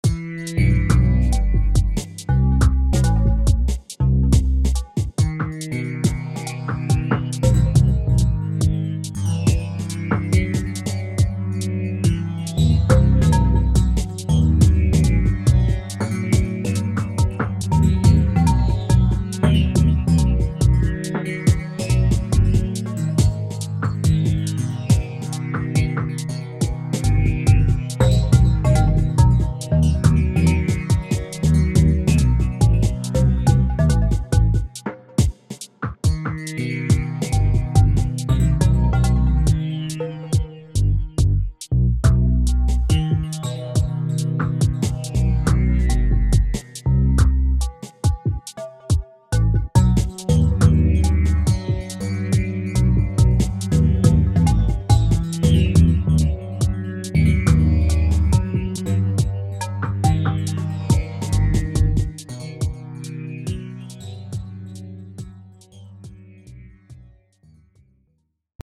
On obtient comme résultat une musique assez inhabituelle, structurée mais sans thème répétitif.
Ce sont 3 compositions "cousines".